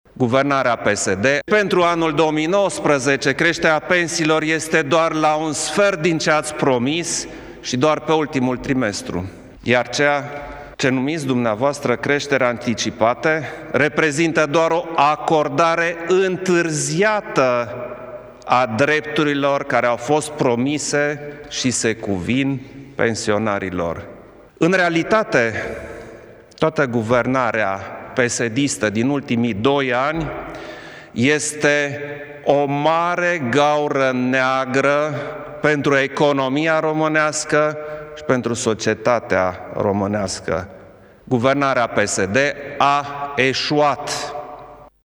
Într-o declarație de presă, președintele României a criticat azi, din nou, în termeni duri bugetul de stat pe 2019, pe care l-a retrimis în parlament pentru a fi modificat și care s-a întors pentru promulgare neschimbat.